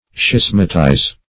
Search Result for " schismatize" : The Collaborative International Dictionary of English v.0.48: Schismatize \Schis"ma*tize\, v. i. [imp.